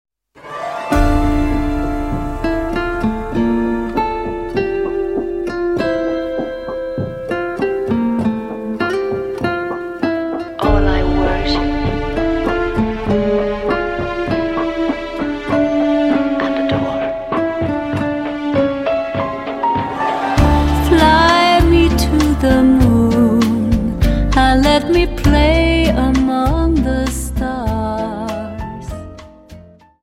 Dance: Rumba 25 Song